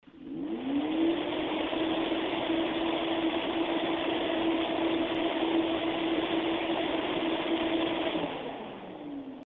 Acoustic Samples: Listen to this Heatsink!
When the large 110mm diameter fan in the Zalman CNPS9700 LED heatsink is powered up to its full speed of 2800RPM, noise levels are moderately high and certainly audible.
frostytech acoustic sampling chamber - full speed
standard waveform view of a 10 second recording. click on the headphones icon to listen to an mp3 recording of this heatsink in operation. the fan is rotating at 2800 rpm
aud_cnps9700led_hi.mp3